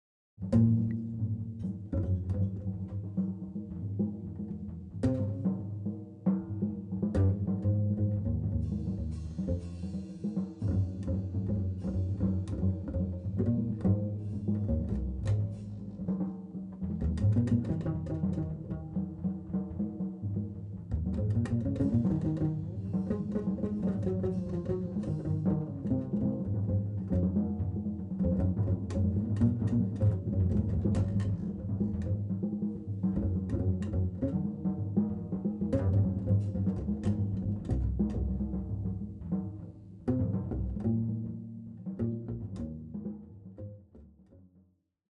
Recorded on at Jazz Spot Candy
いつものように何も決めずに互いに刺激し合い自由にその場で曲を創り上げる。